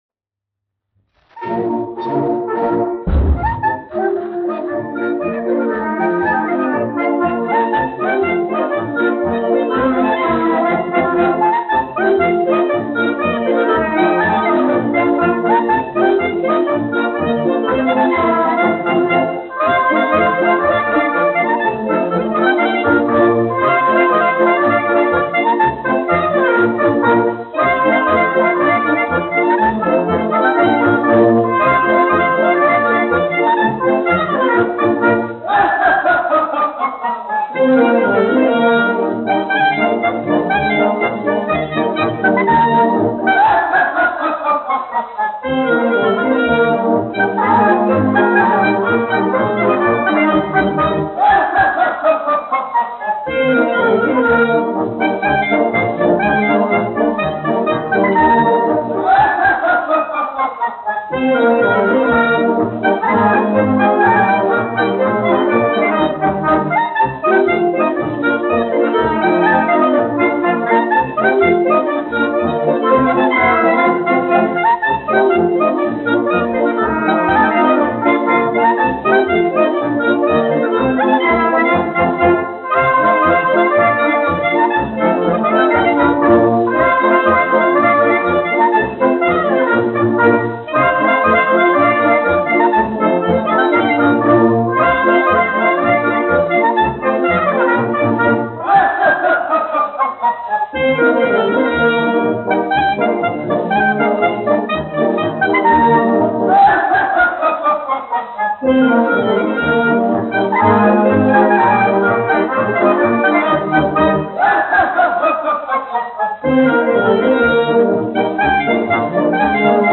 1 skpl. : analogs, 78 apgr/min, mono ; 25 cm
Polkas
Pūtēju orķestra mūzika
Latvijas vēsturiskie šellaka skaņuplašu ieraksti (Kolekcija)